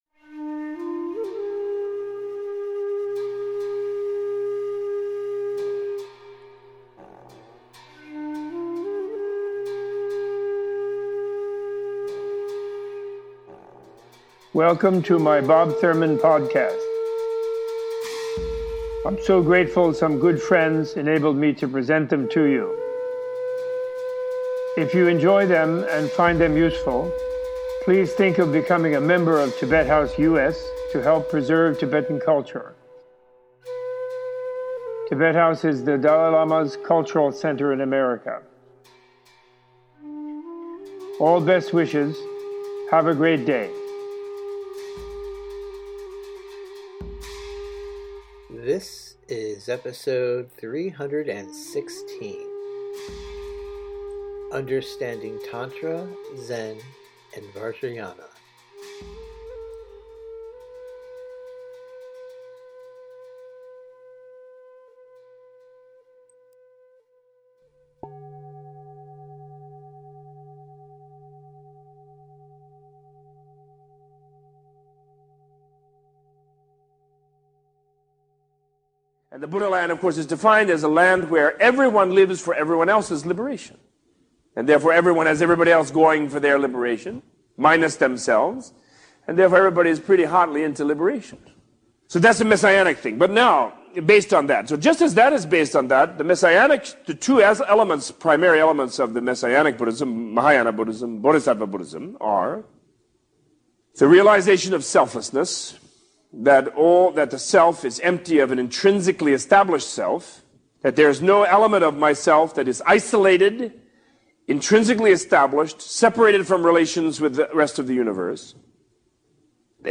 Opening with a definition of Buddha-lands, Robert Thurman gives a teaching on Buddhist Tantra and its interrelationship with Zen, Vajrayana, and all other schools of Buddhism.
-Text From Better Listen Basic Buddhism This episode is an excerpt from the Better Listen “Basic Buddhism” Audio Course.